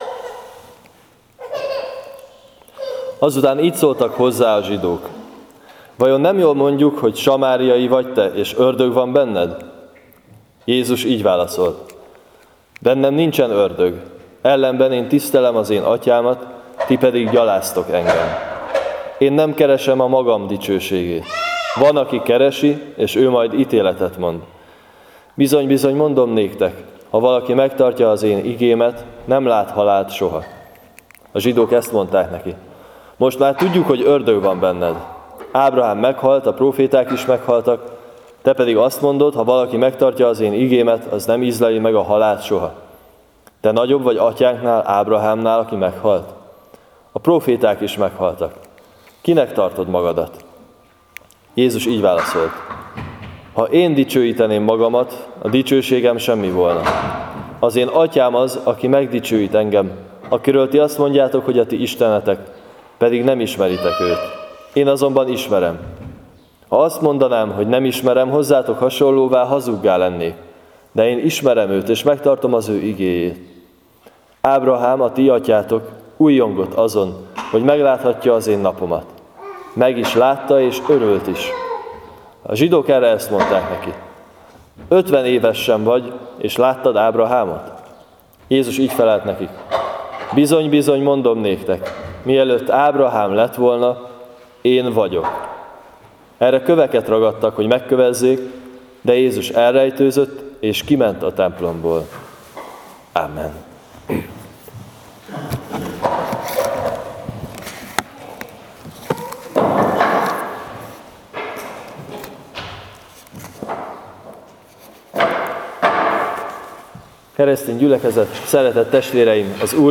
04.02. Jn 8-48-59 igehirdetes.mp3 — Nagycserkeszi Evangélikus Egyházközség